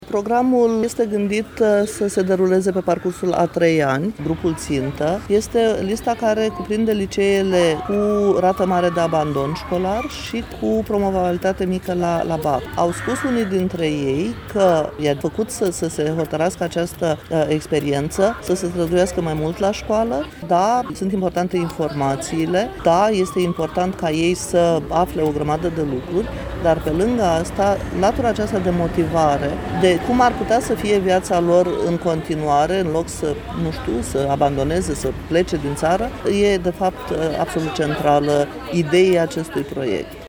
Într-o declarație acordată postului nostru de radio